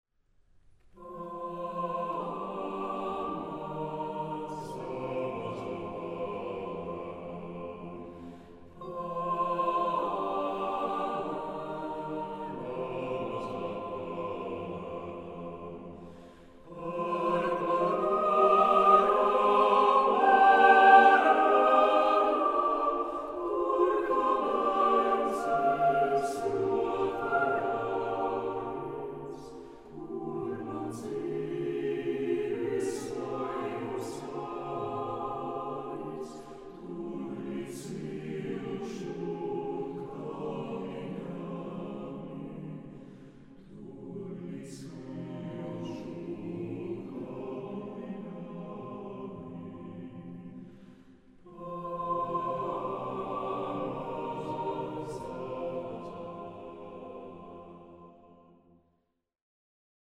Genre: Choral music
Instrumentation: mixed choir